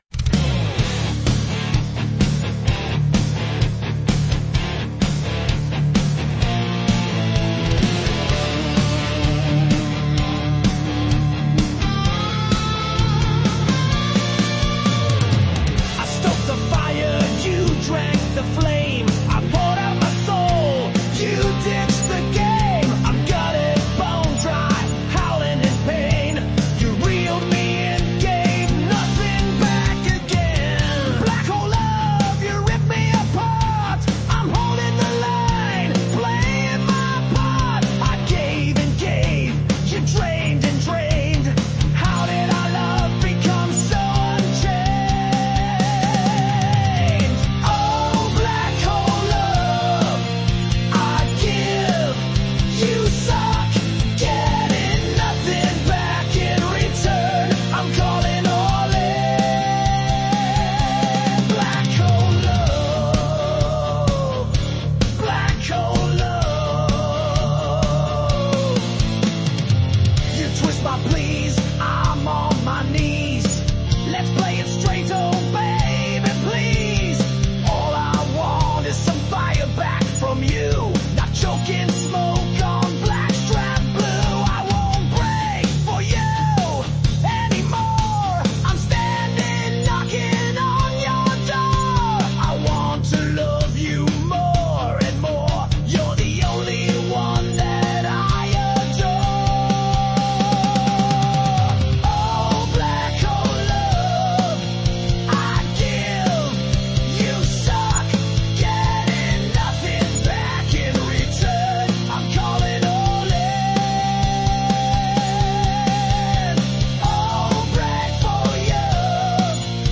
The poetry was a little weak but the message as it relates to the theme and that hard rock AI song was totally cool.
The chorus was especially catchy